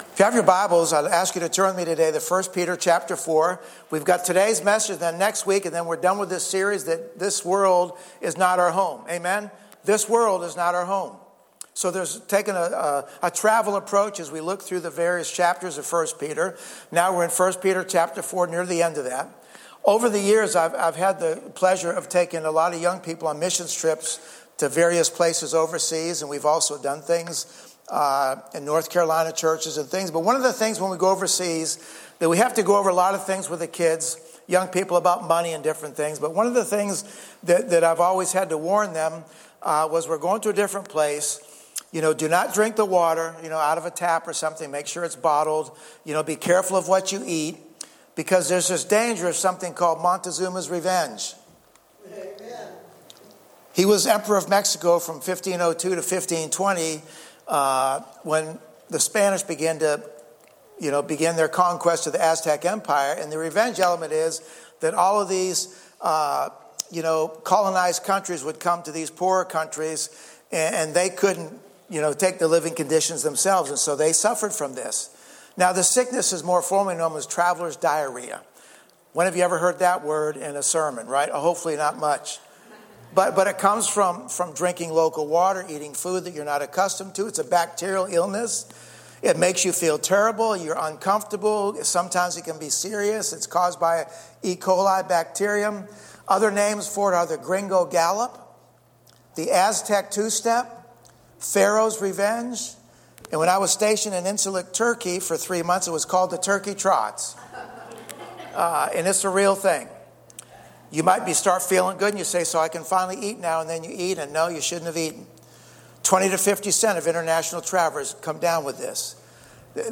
2025 This World is Not Our Home 1 Peter 1 Peter hardship sanctification suffering Sunday Morning Why does God allow us to experience suffering?